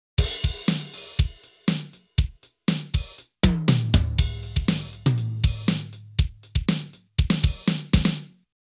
The input audio signal generated by a MIDI sound module is a performance of this score represented by a standard MIDI file (SMF). The tempo is 120 M.M.